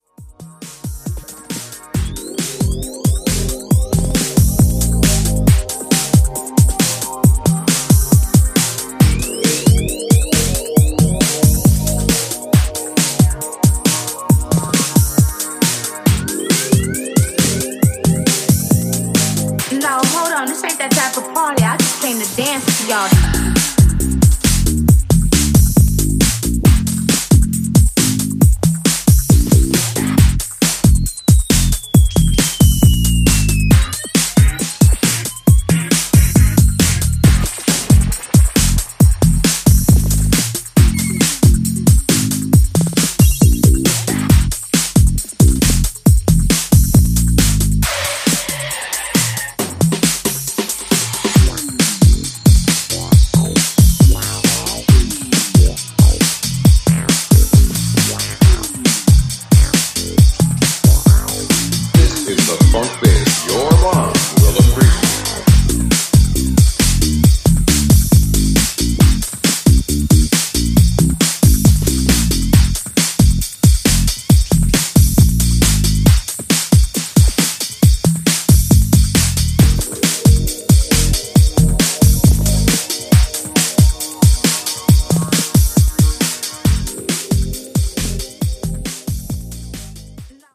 ジャンル(スタイル) HOUSE / DEEP TECH